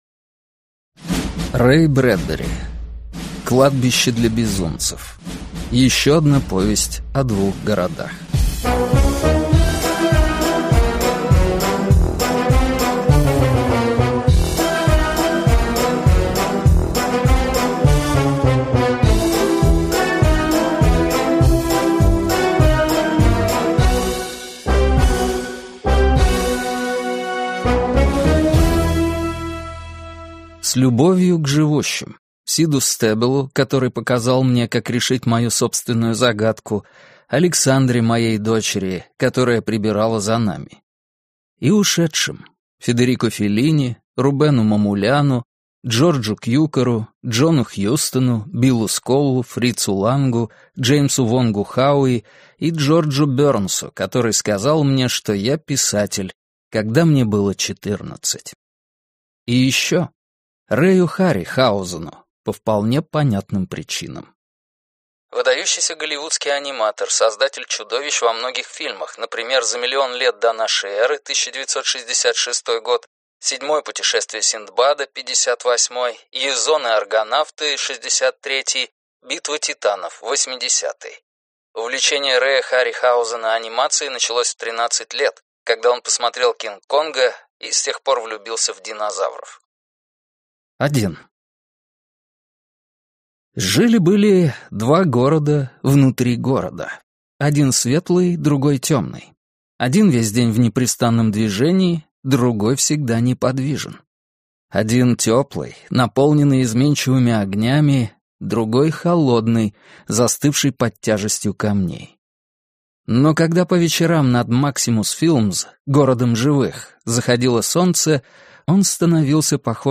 Аудиокнига Кладбище для безумцев. Еще одна повесть о двух городах | Библиотека аудиокниг